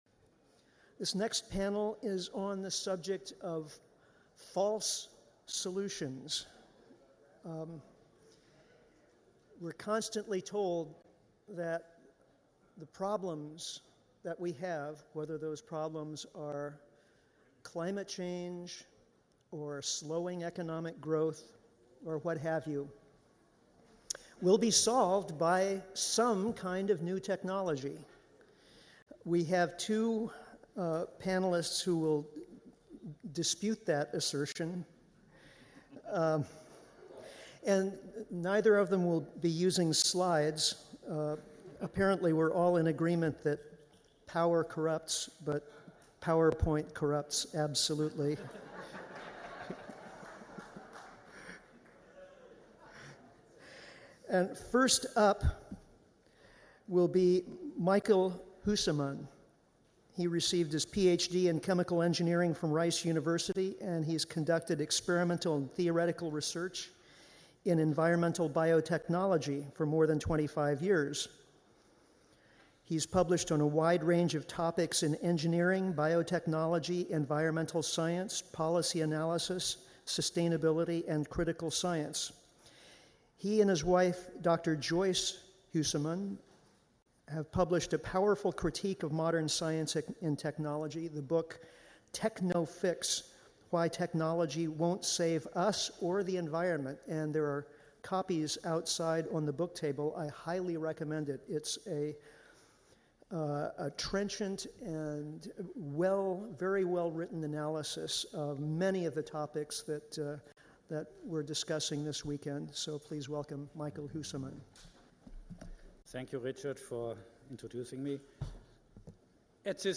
This next panel is on the subject of False Solutions. We’re constantly told that the problems that we have – whether those problems are climate change or slowing economic growth or what have – will be solved by some kind of new technology.
We have two panelists that will dispute that assertion and neither of them will be using slides; apparently we’re all in agreement that power corrupts but power point corrupts absolutely.